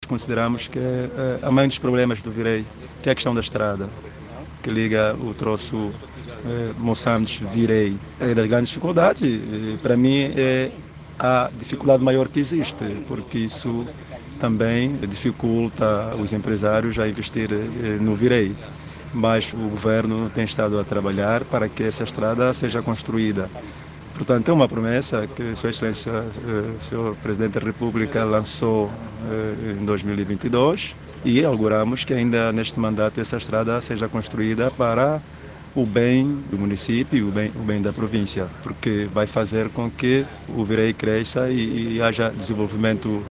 O administrador municipal aponta a construção da estrada Virei/Moçâmedes como uma das maiores preocupações daquela administração municipal. André Cassinda diz mesmo que a construção da estrada Virei – Moçâmedes vai levar o desenvolvimento à região.